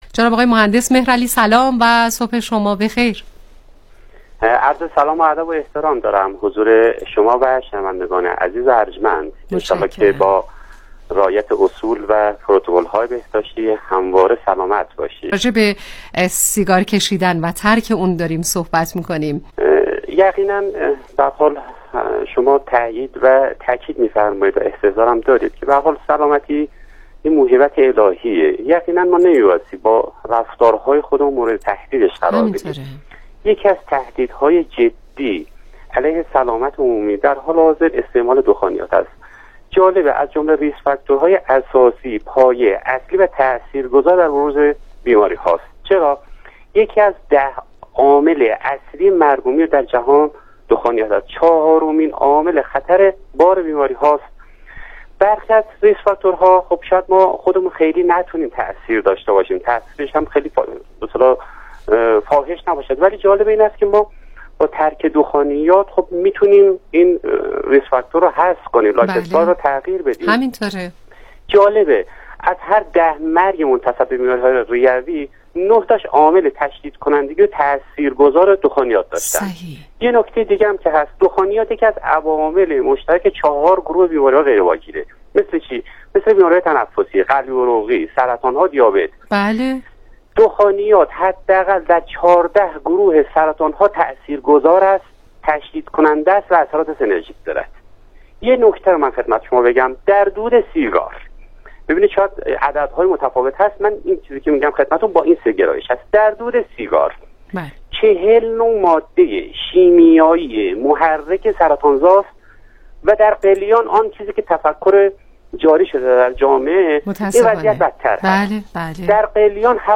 گفتگوی تلفنی
برنامه رادیویی کانون مهر
ستعمال قلیان و راه های ترک آن از صدای شبکه آفتاب